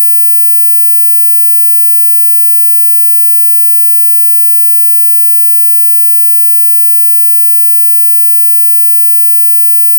Estranho zumbido que só alguns conseguem ouvir: a frequência usada para afastar jovens vândalos.
17.4_kHz_sine_wave.flac